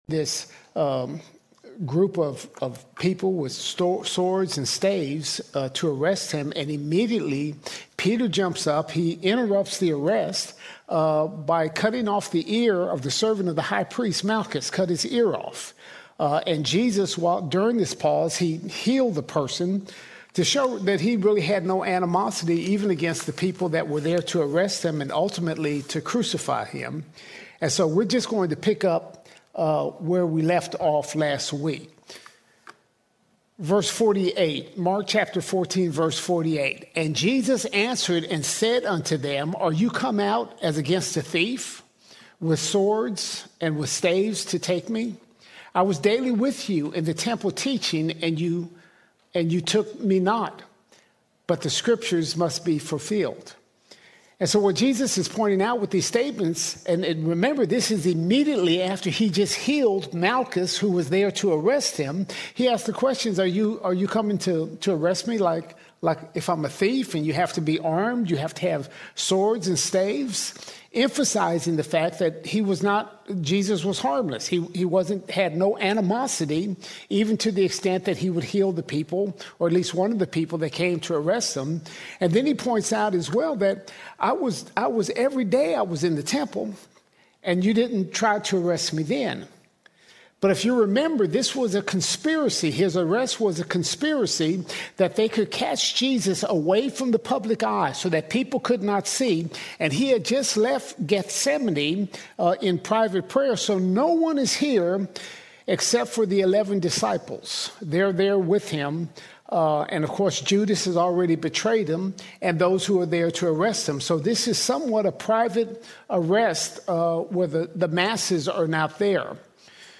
16 April 2026 Series: Gospel of Mark All Sermons Mark 14:48 - 15:20 Mark 14:48 – 15:20 Jesus confronts the mob’s hypocrisy, is abandoned, falsely accused, mocked, and condemned.